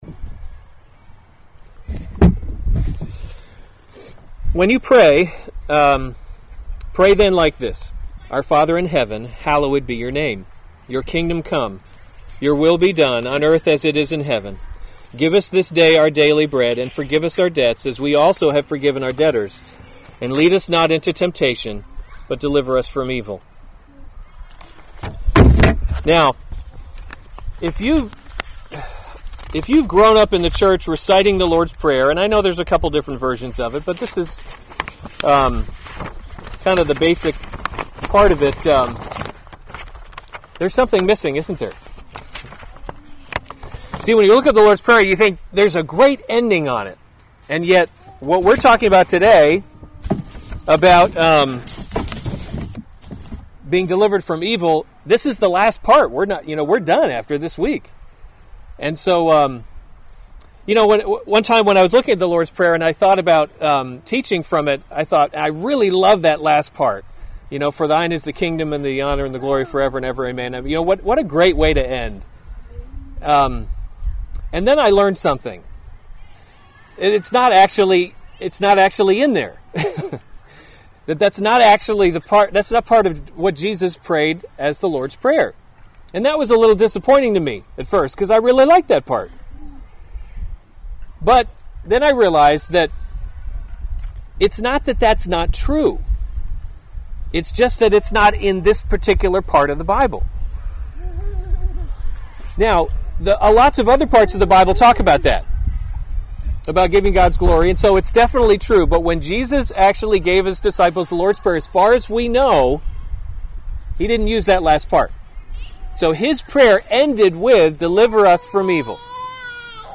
August 5, 2017 Lord’s Prayer: Kingdom Come series Weekly Sunday Service Save/Download this sermon Matthew 6:13 Other sermons from Matthew 13 And lead us not into temptation, but deliver us from […]